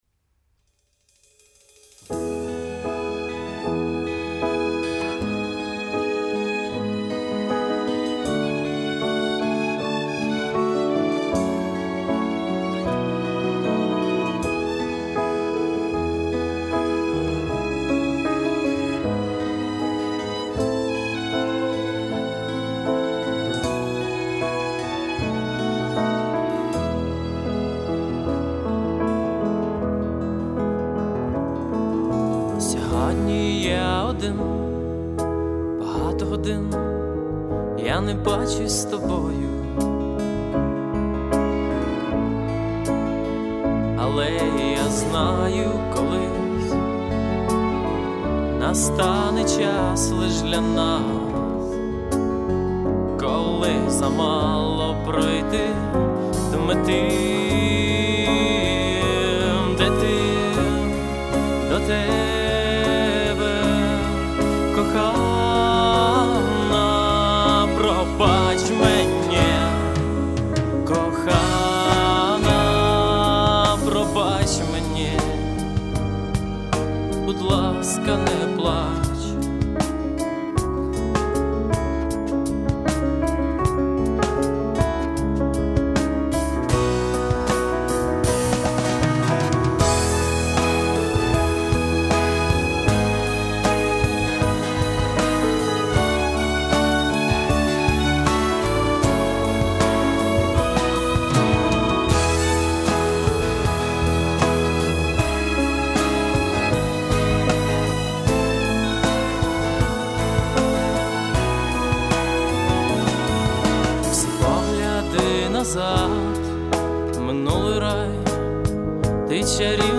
Стиль: украинский брит-поп, украинский инди-рок.